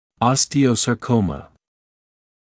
[ˌɔstɪə(u)ˌsɑː’kəumə][ˌостио(у)ˌсаː’коумэ]остеосаркома